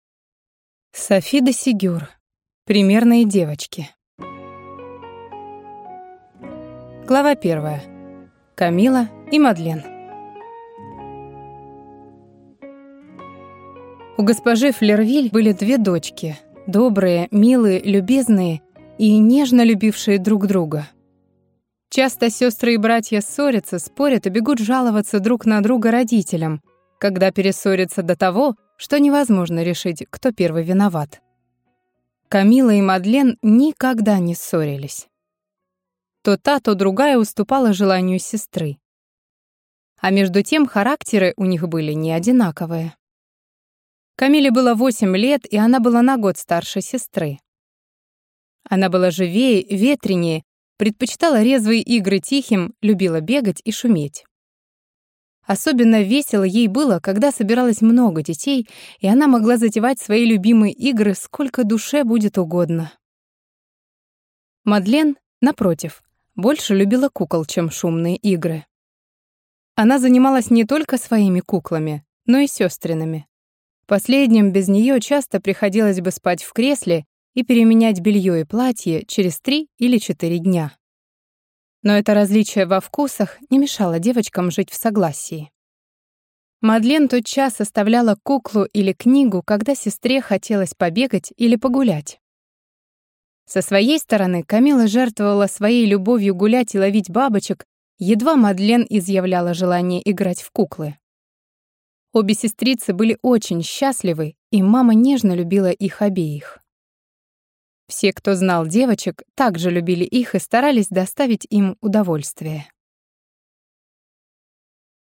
Аудиокнига Примерные девочки | Библиотека аудиокниг